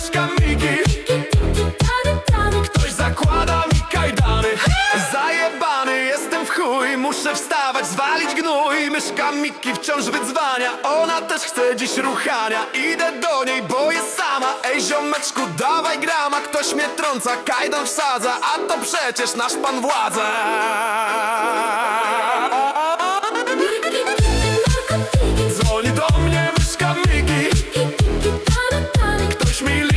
Жанр: Поп музыка / Танцевальные
Dance, Pop